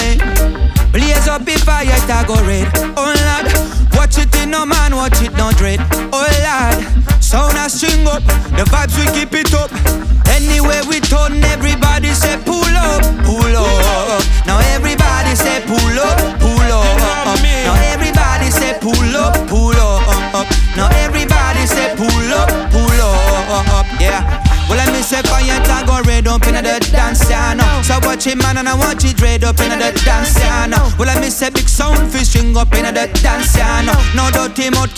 Жанр: Регги